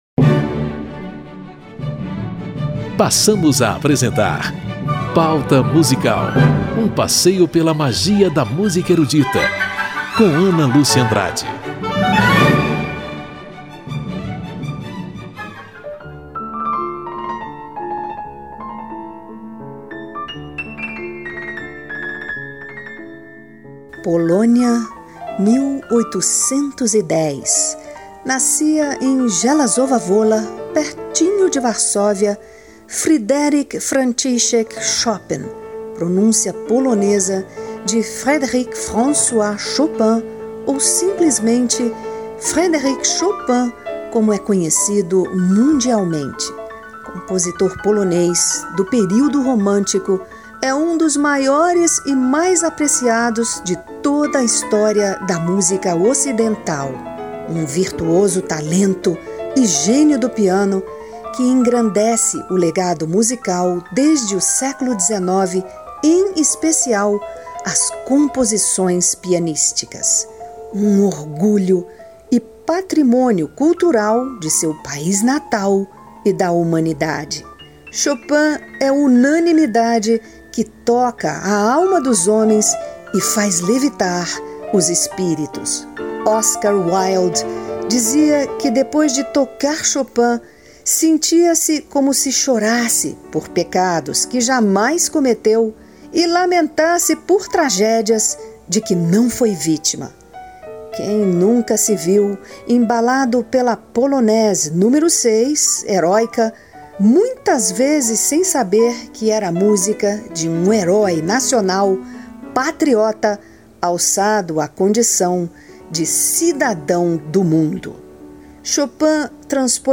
Um admirado compositor polonês em série especial com duas edições. Filarmônica de Varsóvia, sob a regência do maestro Antoni Wit, Rafał Blechacz e Adam Harasiewicz, solistas premiados no Concurso Internacional de Piano Frédéric Chopin, interpretam a Polonaise n. 6 "Heroica" e o Concerto para Piano e Orquestra n. 1, de Frédéric Chopin.